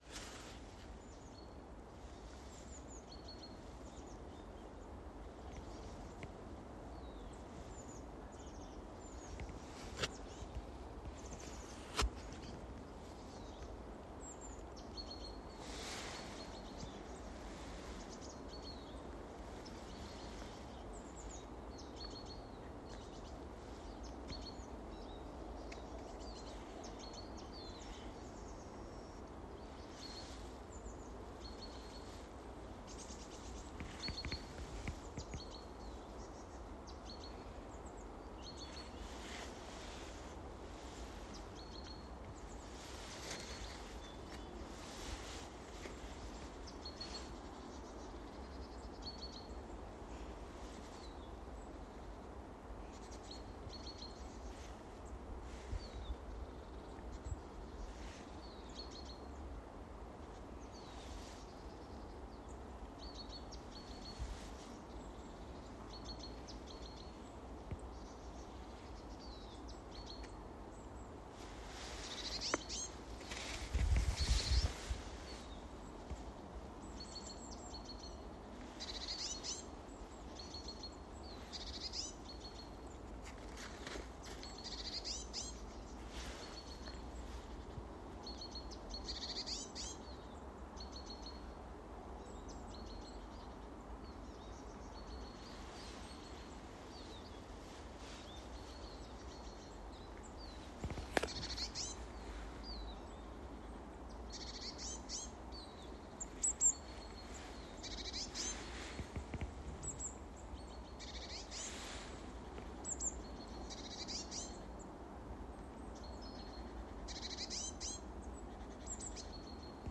чиж, Spinus spinus
Skaits10 - 20
Koka galotnē dzied žubīte.